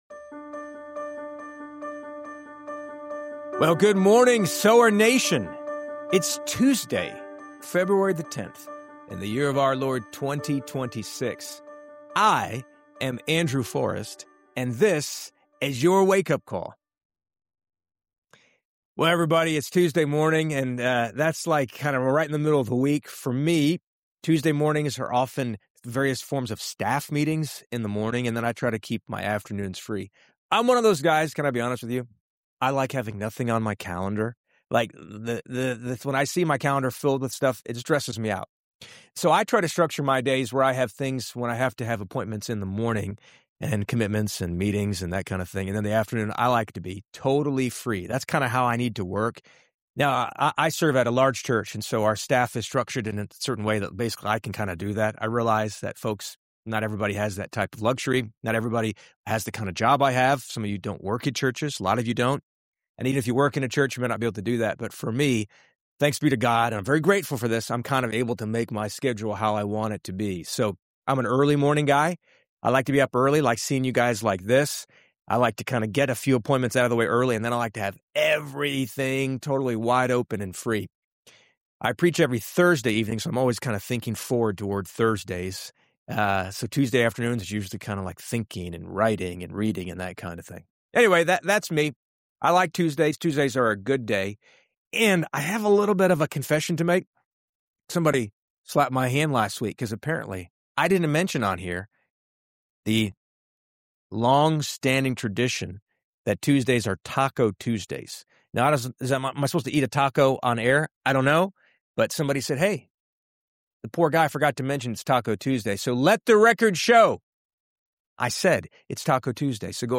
Cap it off with a heart-stirring rendition of the hymn “Blessed Assurance,” and you’ll leave this episode with renewed confidence in your God-given abilities.